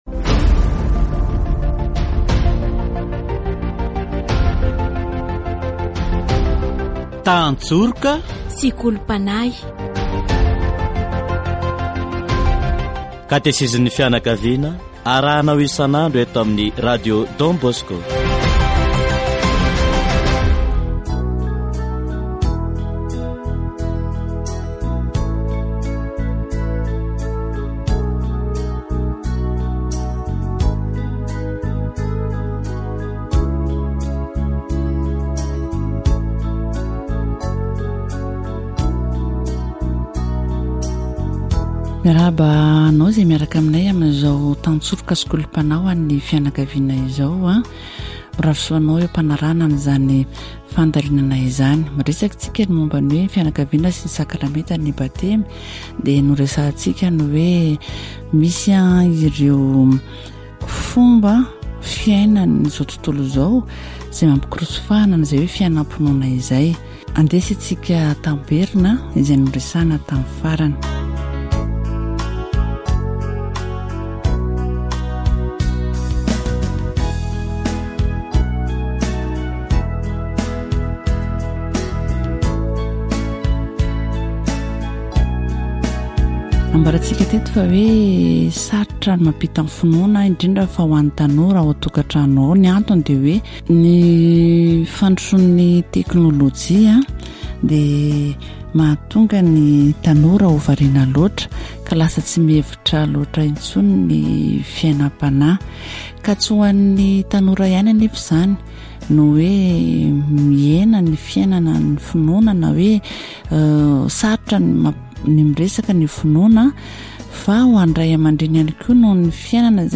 Catégorie : Approfondissement de la foi
Catéchèse sur la famille et le sacrement du baptême